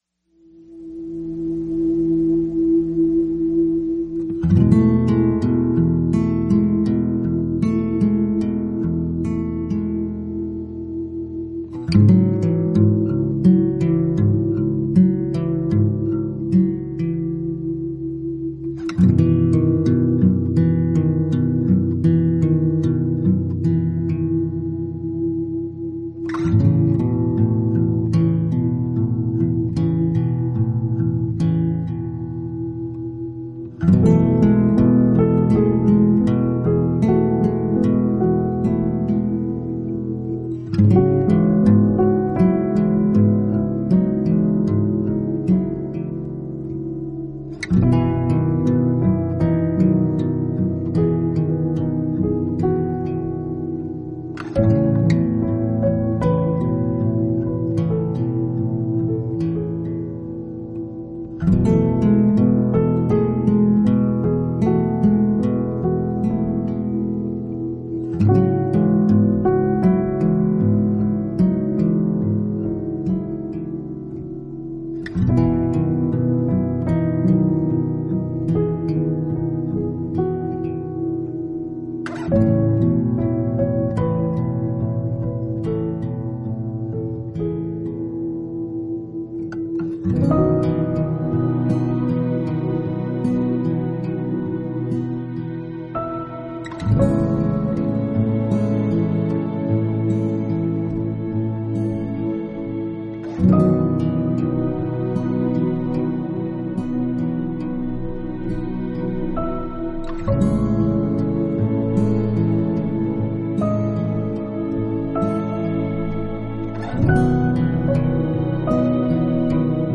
an Instrumental Devotional